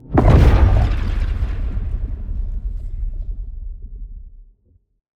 cdf_blowout.ogg.bak